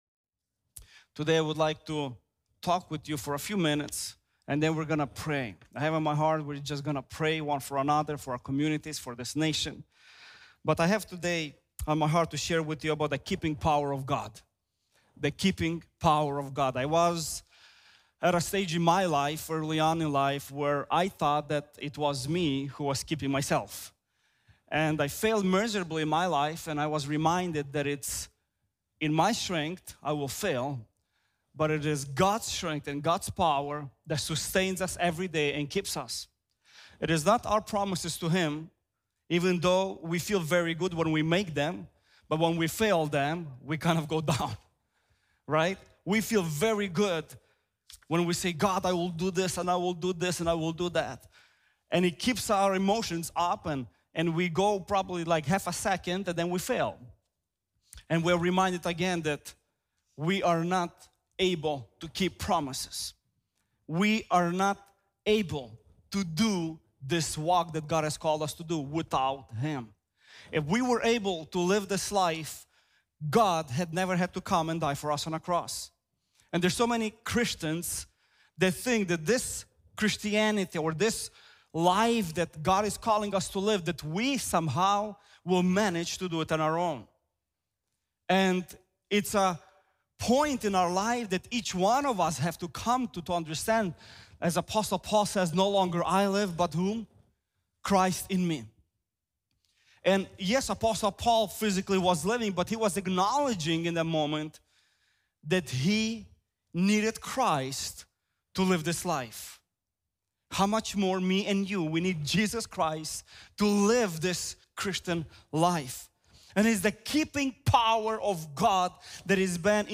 The Keeping Power of God | Times Square Church Sermons